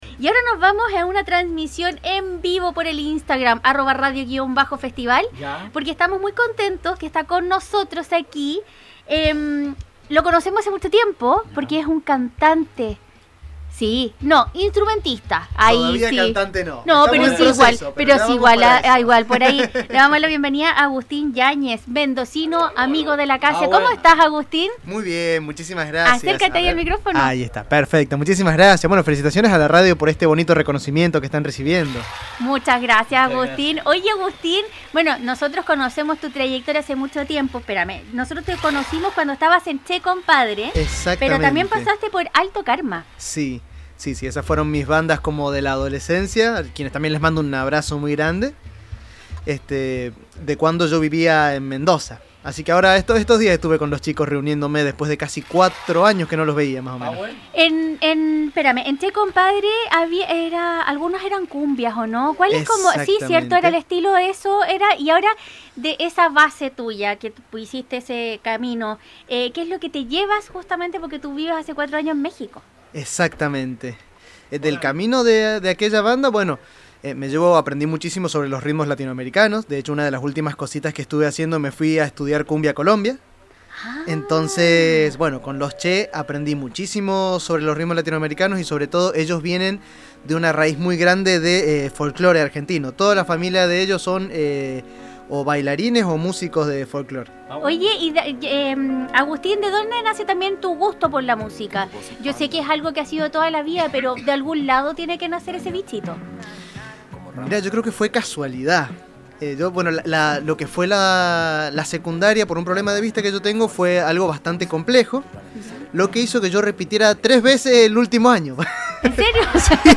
en los estudios de Radio Festival